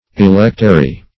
electary - definition of electary - synonyms, pronunciation, spelling from Free Dictionary Search Result for " electary" : The Collaborative International Dictionary of English v.0.48: Electary \E*lec"ta*ry\, n. (Med.) See Electuary .